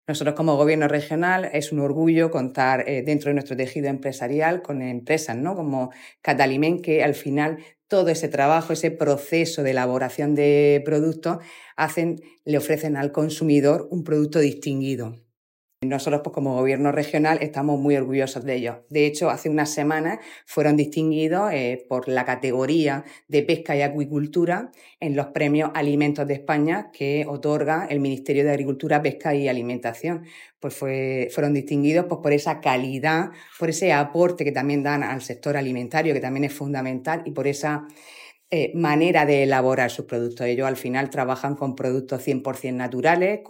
Contenidos Asociados: Declaraciones de la consejera Sara Rubira tras la visita que ha realizado a empresa Cataliment Perlas (Documento [.mp3] 0,66 MB) Destacados Conciliación laboral (SMAC) e-Tributos Pago a Acreedores Participación ciudadana Canal Mar Menor © Todos los derechos res